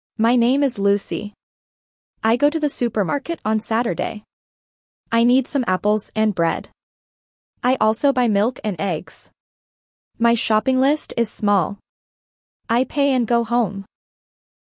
Dictation A1 - At the Supermarket
2.-A1-Dictation-At-the-Supermarket.mp3